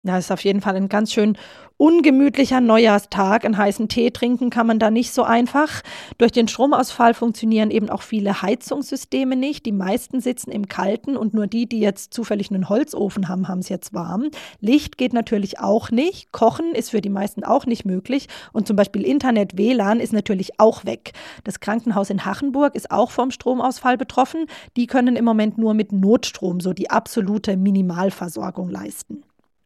Nachrichten Stromausfall im Westerwald